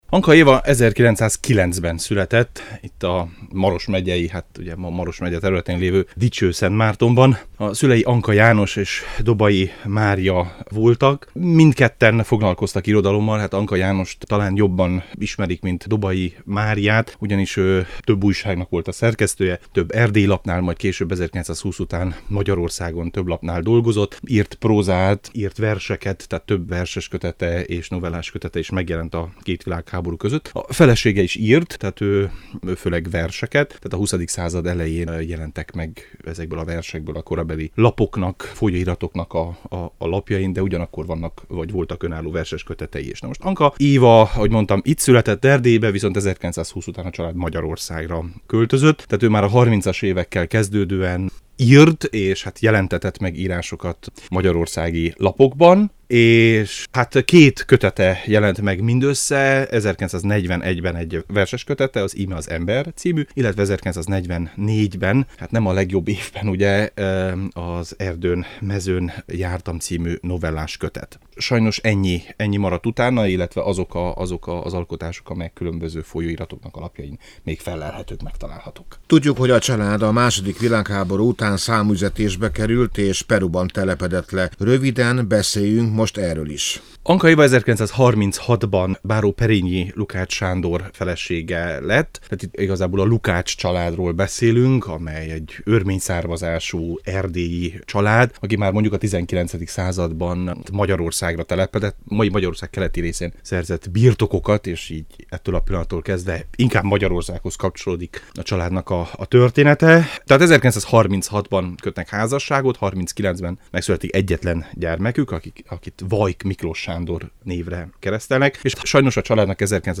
beszélgetett